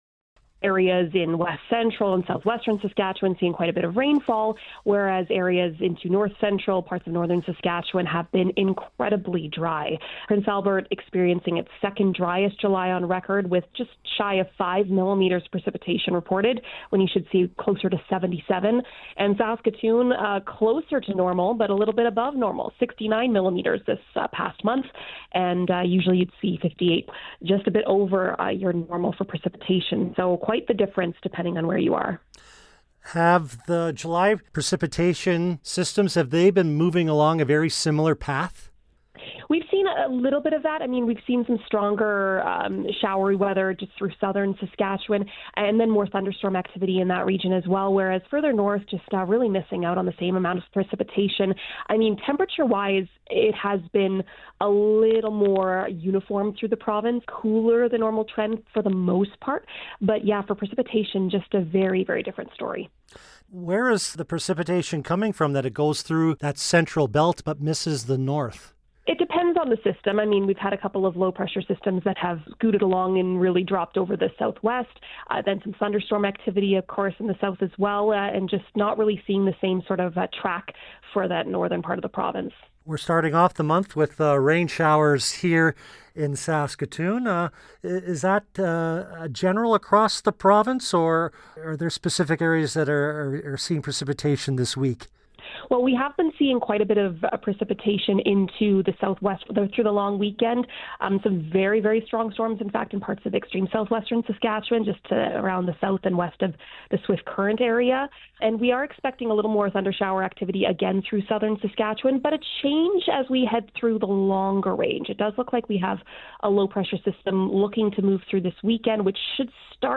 spoke to weather scientist